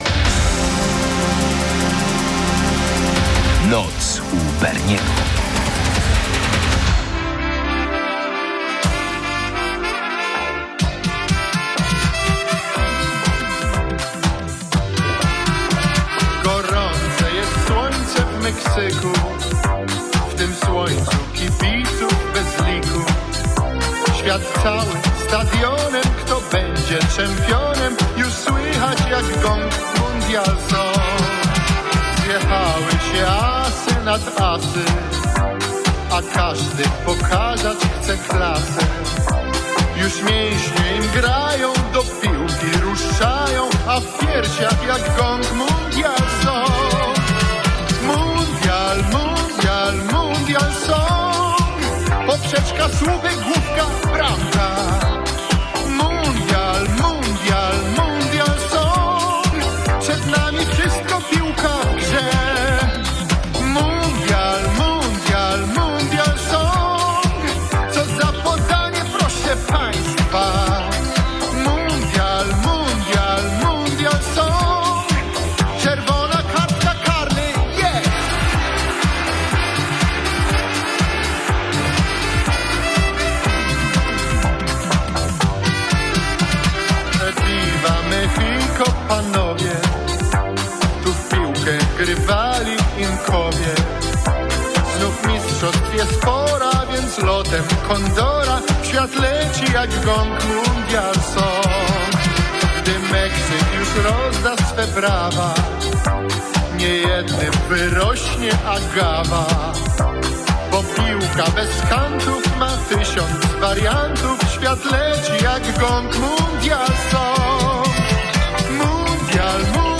Dominujący gatunek: polskie piosenki z 1986 roku